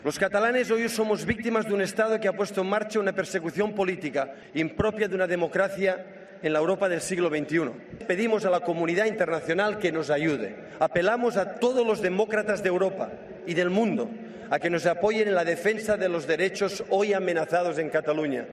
El exentrenador del Barça y actual técnico del Manchester City, Josep Guardiola, ha leído este domingo un manifiesto en nombre de las principales entidades soberanistas, en el que ha hecho una apelación al mundo a apoyar el referéndum en Cataluña: "Pedimos a la comunidad internacional que nos ayude".
En un multitudinario acto frente al Monumento de las Cuatro Columnas en Montjuïc, en Barcelona, bajo el lema "Referéndum es democracia", el mundo soberanista ha exhibido músculo en su primera demostración de fuerza después de que el presidente catalán, Carles Puigdemont, anunciara su intención de convocar un referéndum sobre la independencia de Cataluña para el próximo 1 de octubre.
"Defenderemos con todas nuestras fuerzas la democracia y a nuestros representantes", ha afirmado Guardiola, entre gritos de "Independencia" y "Votaremos".